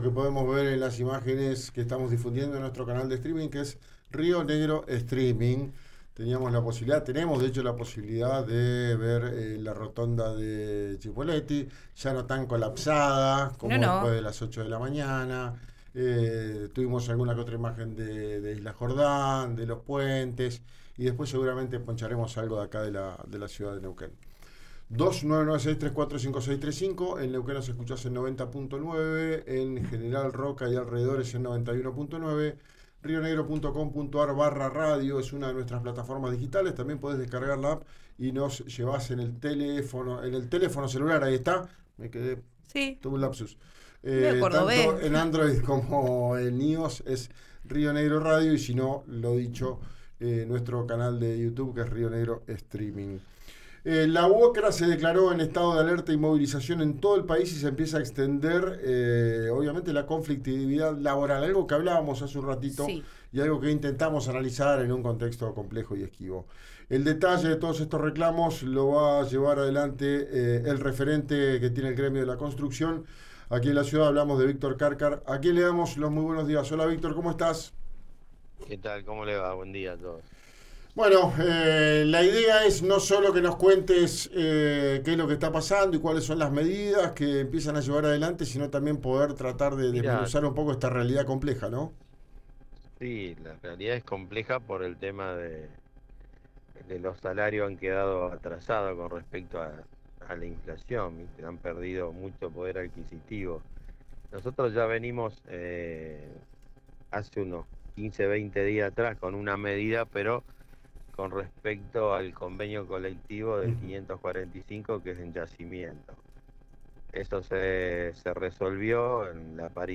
en una entrevista con RÍO NEGRO RADIO habló sobre la situación de los trabajadores y su preocupación por los salarios desnivelados.